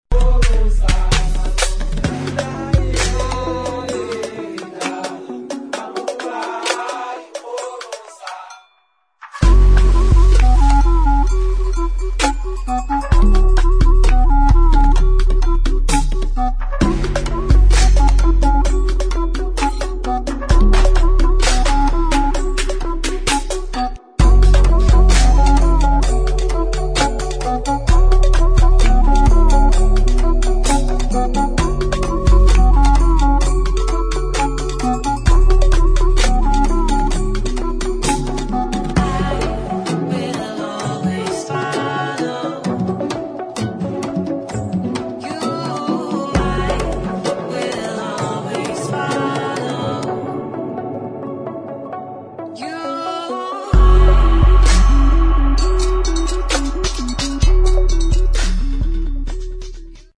[ DOWNTEMPO ]